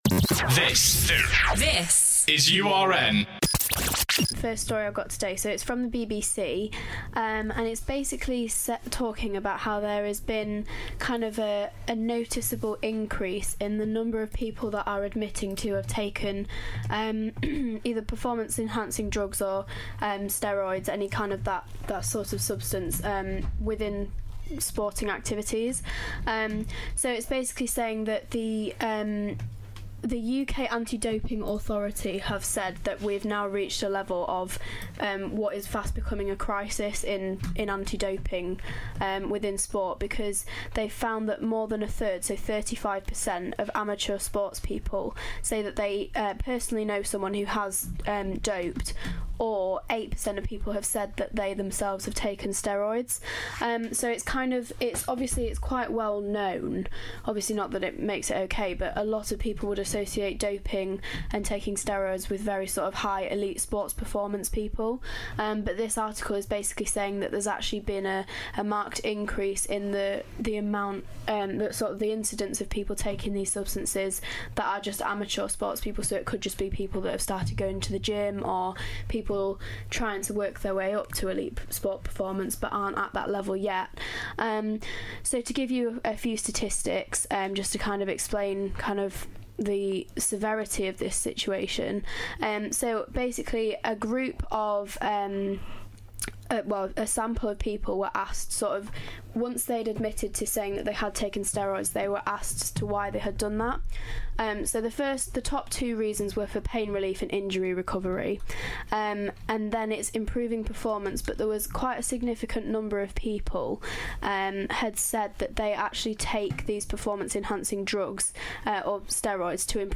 Discussion on Doping in sport among young people